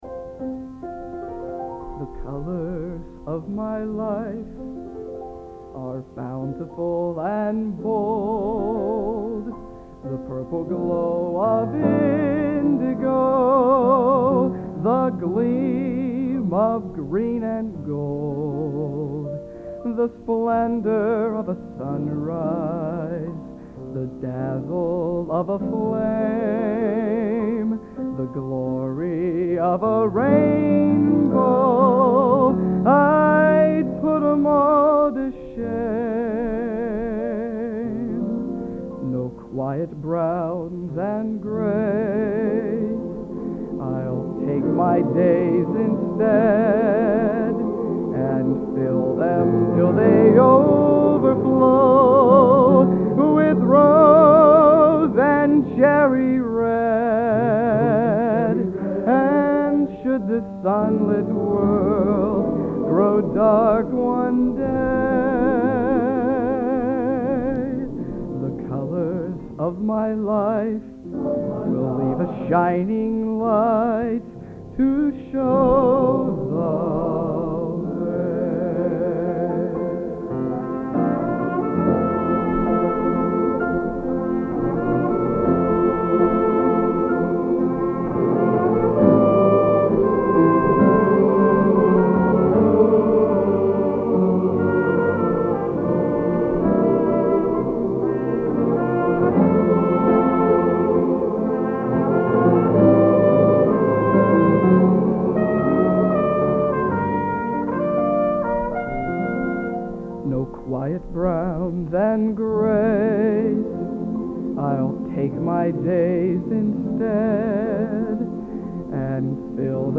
Location: West Lafayette, Indiana